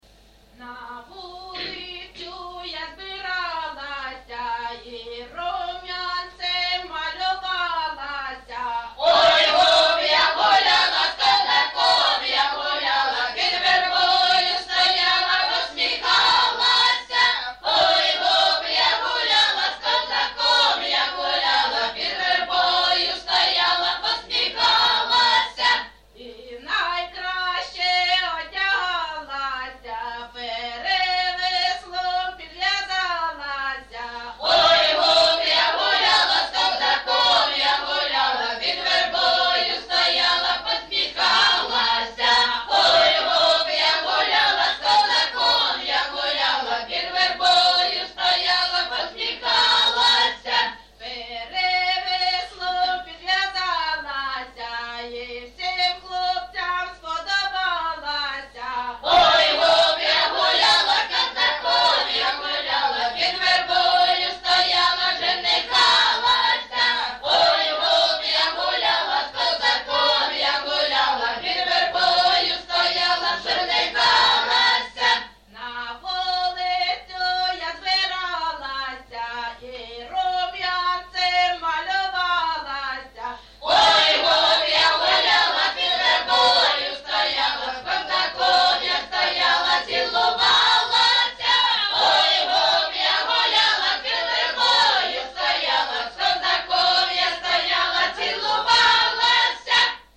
ЖанрПісні з особистого та родинного життя
Місце записус-ще Щербинівка, Бахмутський район, Донецька обл., Україна, Слобожанщина